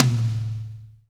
-DRY TOM 4-L.wav